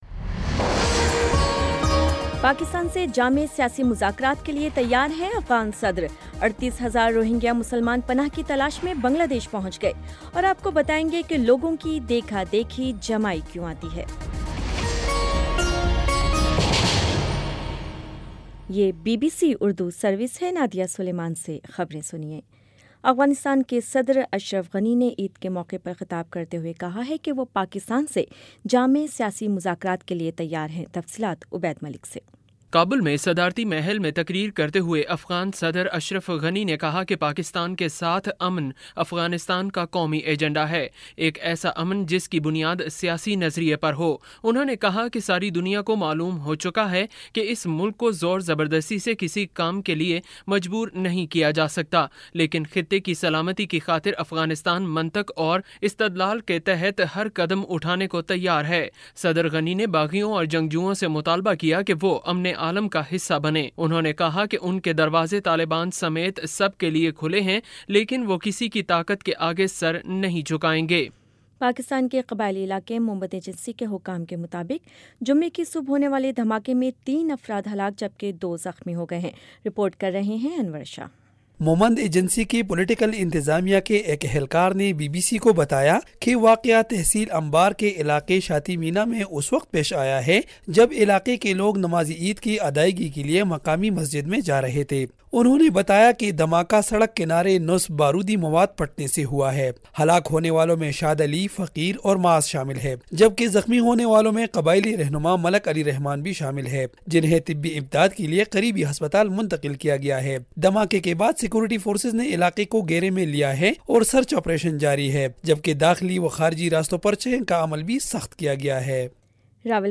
ستمبر 01 : شام چھ بجے کا نیوز بُلیٹن